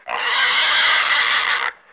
جلوه های صوتی
دانلود صدای حیوانات جنگلی 25 از ساعد نیوز با لینک مستقیم و کیفیت بالا